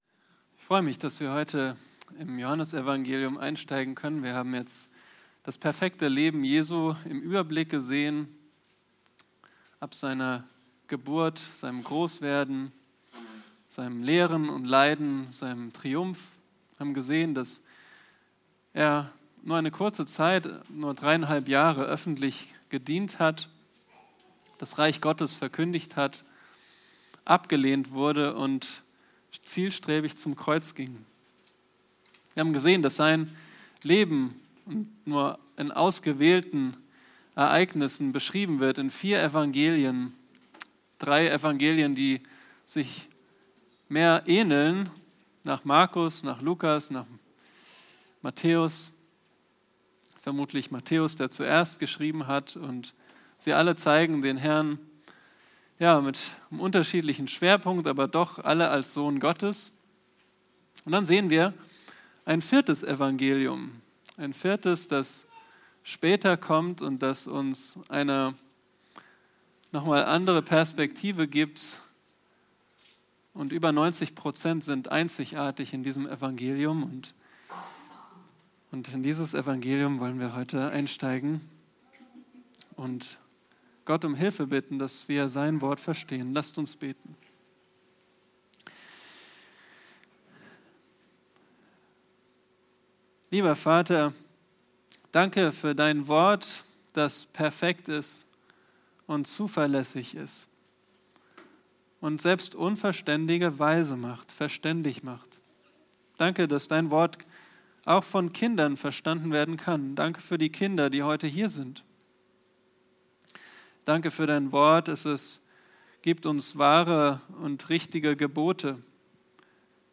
Predigten - Übersicht nach Serien - Bibelgemeinde Barnim